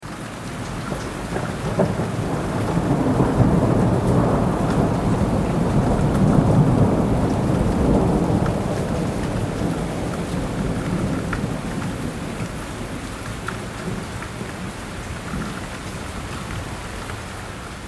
دانلود آهنگ طوفان 18 از افکت صوتی طبیعت و محیط
جلوه های صوتی
دانلود صدای طوفان 18 از ساعد نیوز با لینک مستقیم و کیفیت بالا